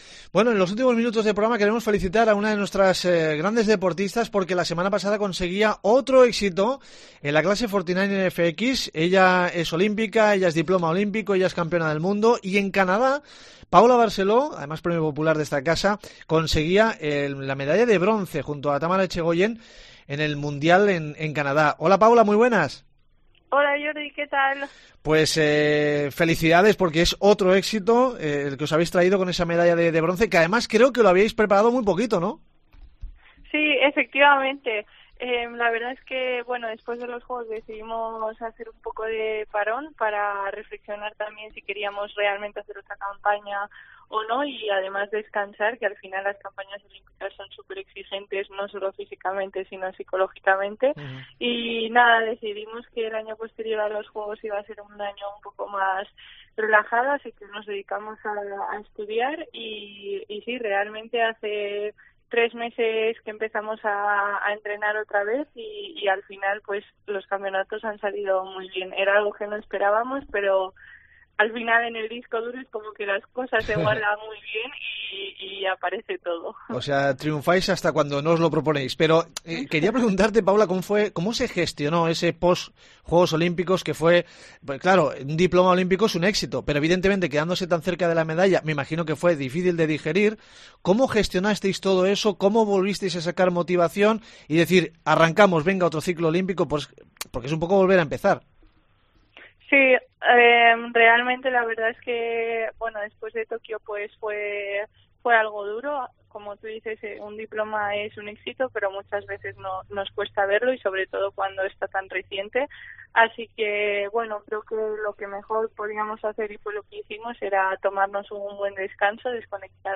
Hablamos con Paula Barceló sobre cómo ha sido el proceso y cómo afrontan el nuevo ciclo oímpico